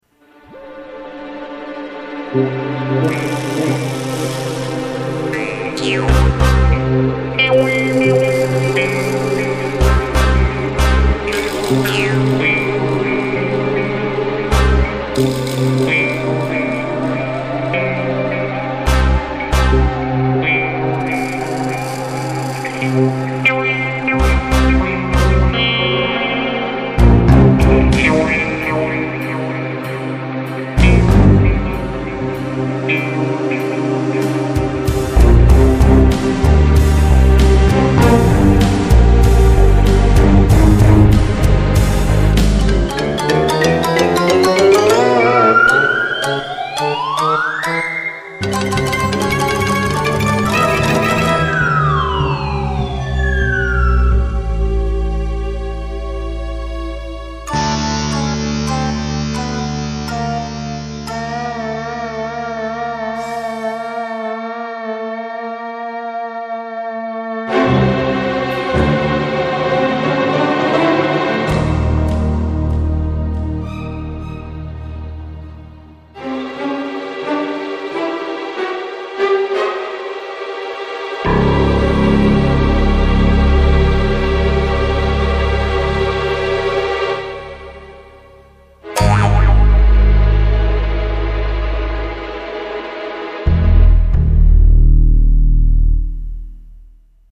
I demonstrate many of the things I talked about, cheesy muted jazz tbones, ratchets, cheesy diminished chords played by tremelo strings....hehe and jawharp)
I did it because it was a comedic scene.